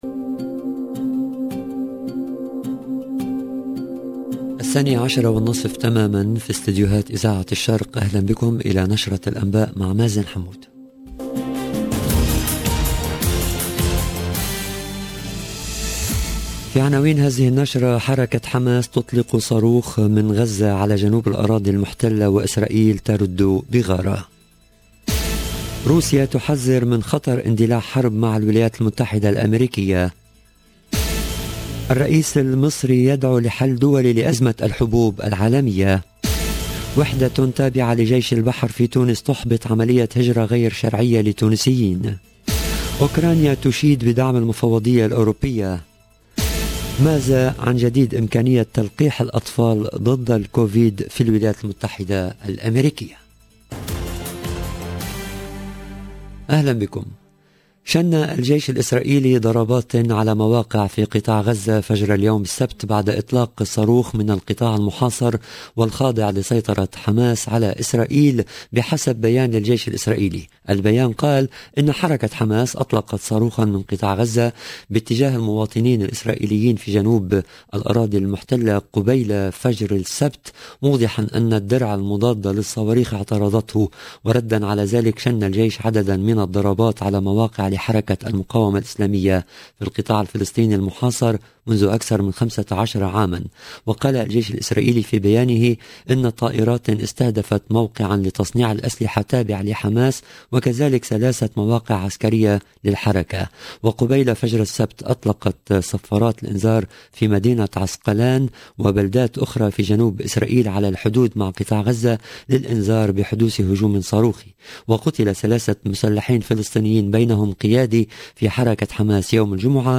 LE JOURNAL DE 12H30 EN LANGUE ARABE DU 18/6/2022